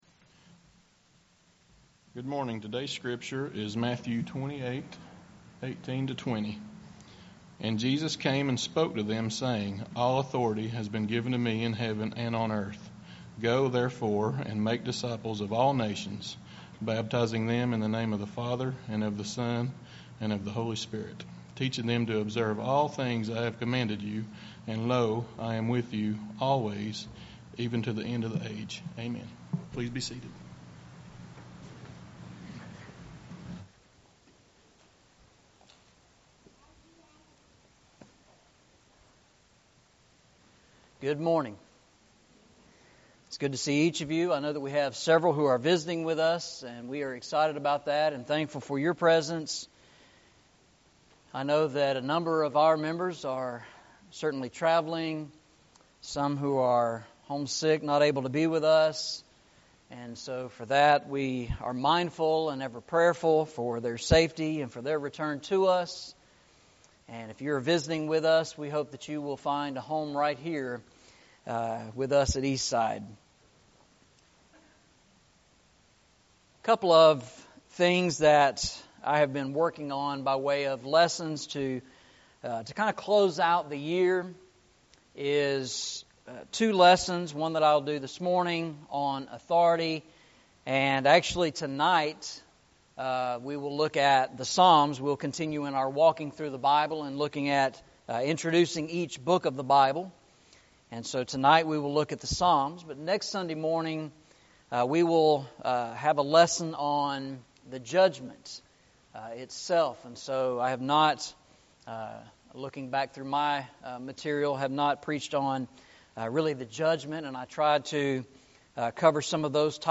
Eastside Sermons
Matthew 28:18-20 Service Type: Sunday Morning « Walking Through the Bible